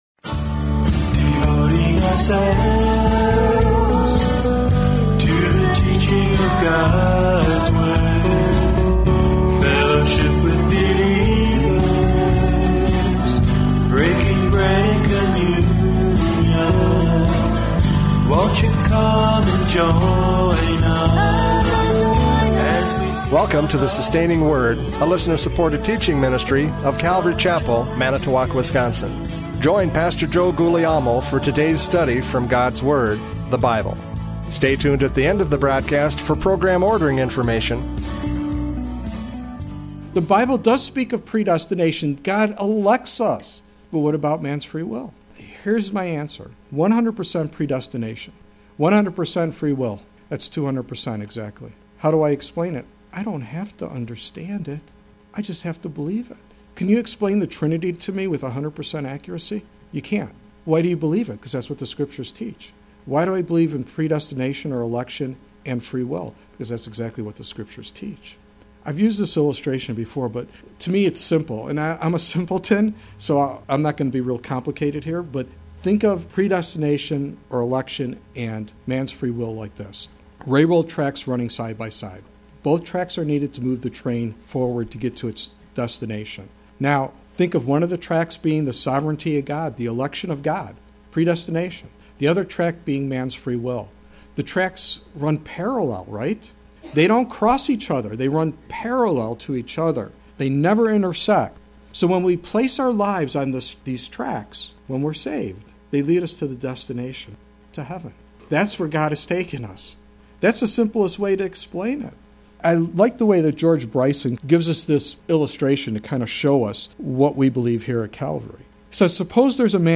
John 6:41-59 Service Type: Radio Programs « John 6:41-59 The Bread of Life!